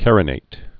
(kărə-nāt, -nĭt) also car·i·nat·ed (-nātĭd)